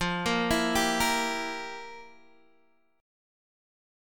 FM7sus2sus4 chord